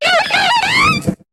Cri de Chimpenfeu dans Pokémon HOME.